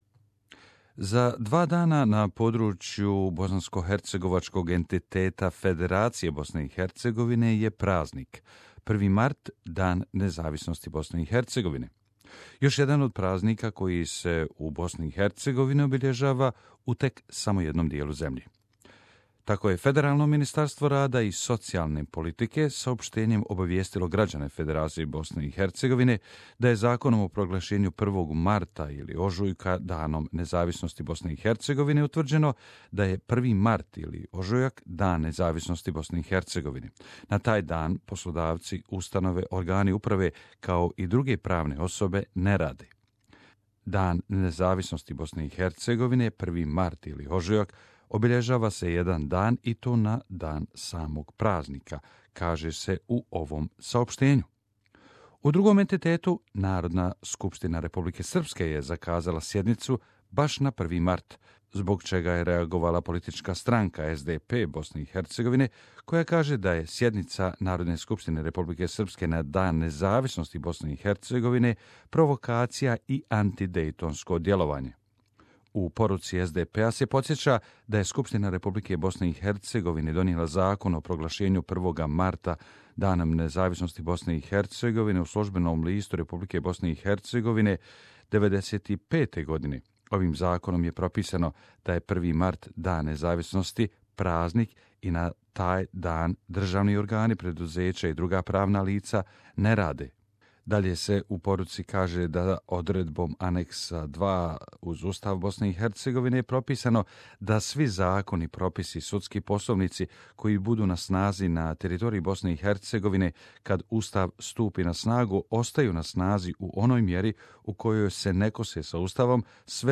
Independence Day of Bosnia and Herzegovina, report